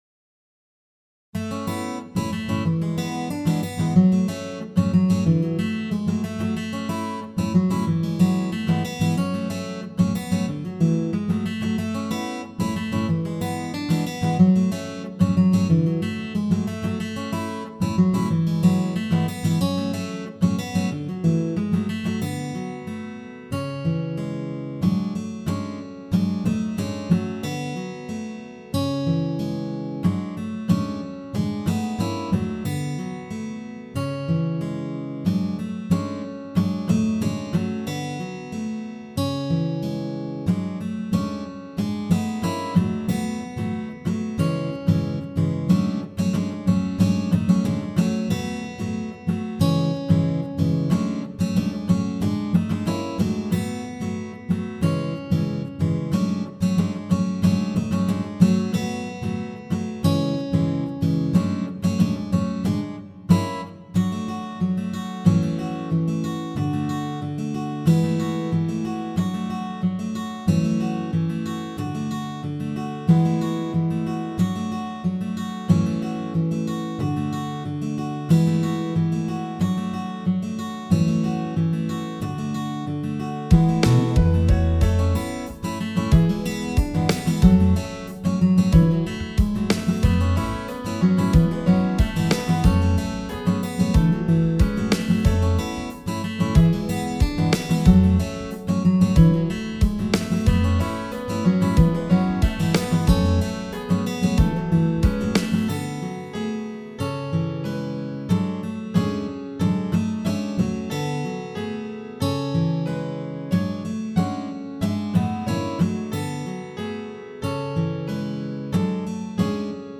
カントリーロング穏やか